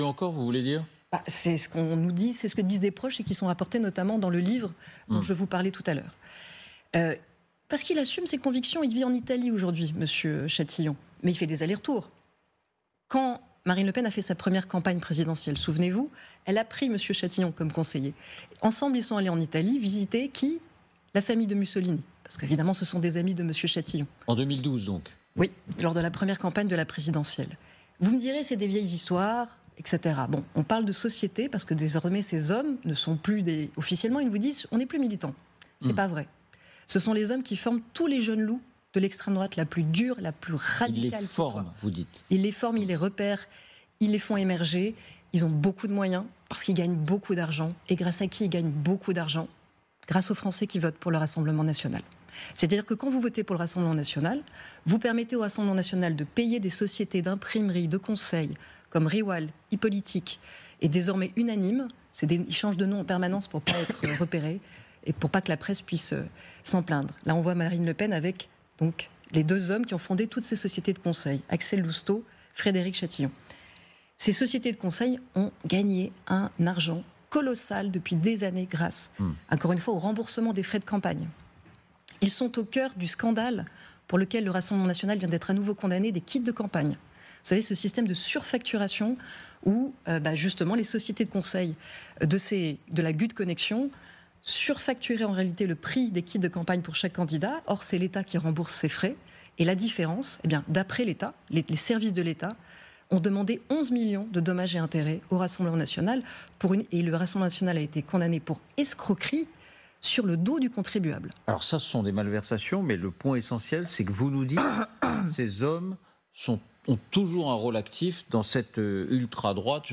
Caroline Fourest, sur les ondes de LCI, nous informe du RN et de son entourage, ses principaux conseillers qui sont tous des suprémacistes blancs, des petits amis de Poutine qu'ils soutiennent ouvertement en France.